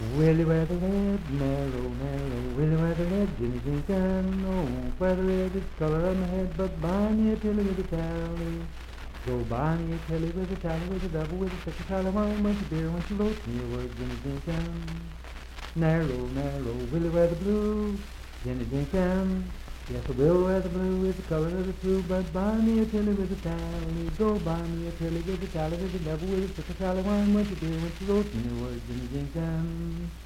Unaccompanied vocal music
Dance, Game, and Party Songs
Voice (sung)